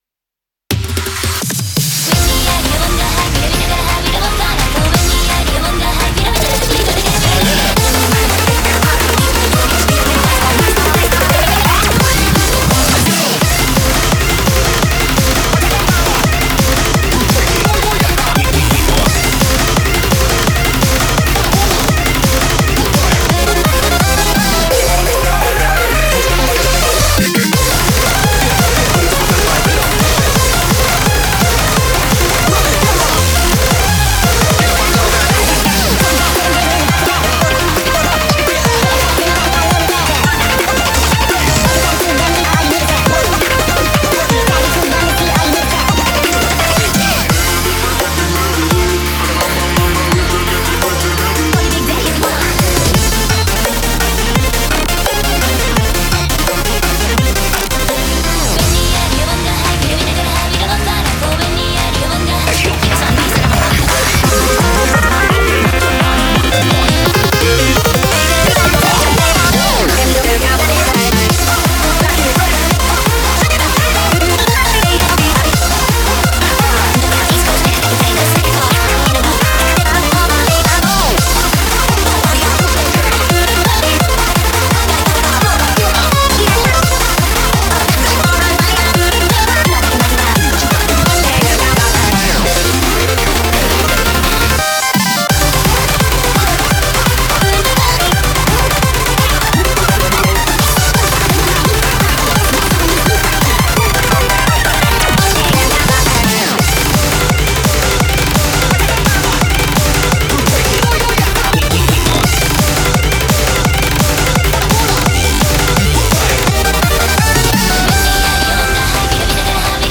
Audio QualityPerfect (High Quality)